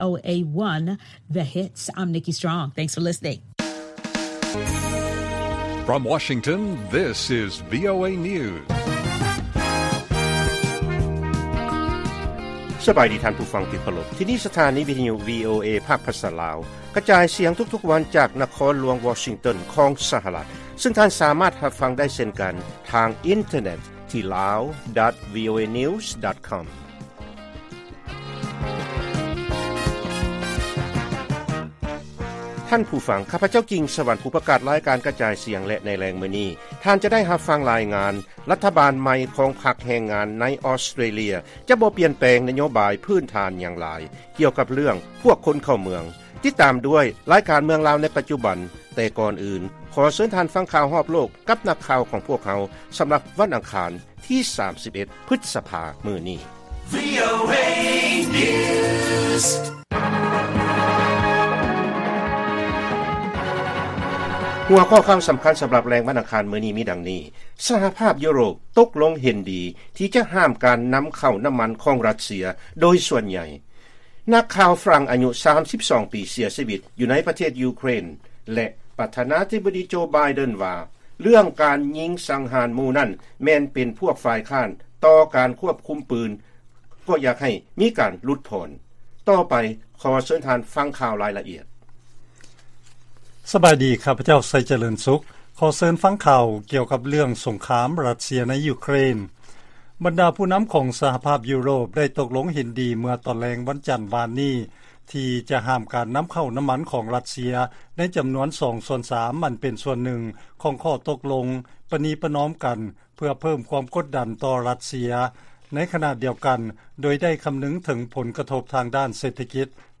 ລາຍການກະຈາຍສຽງຂອງວີໂອເອ ລາວ: ສະຫະພາບຢູໂຣບ ຕົກລົງເຫັນດີ ທີ່ຈະຫ້າມການນຳເຂົ້ານ້ຳມັນຂອງຣັດເຊຍ ໂດຍສ່ວນໃຫຍ່